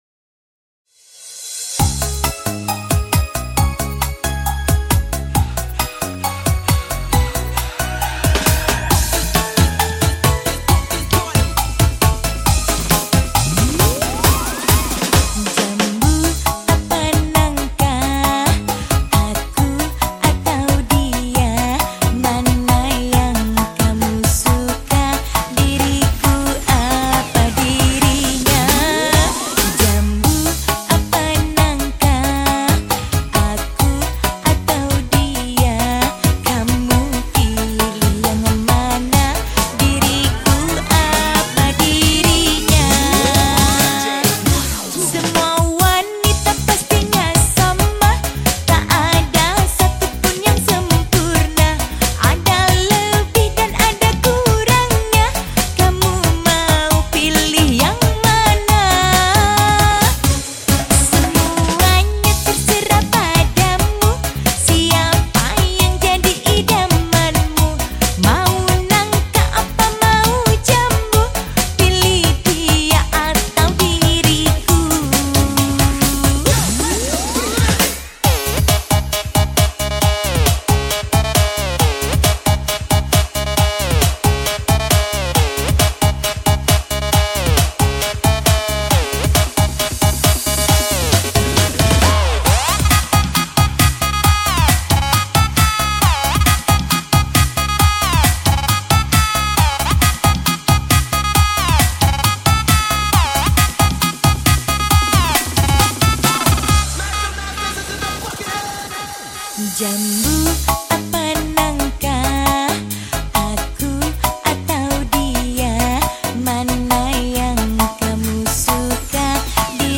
penyanyi dangdut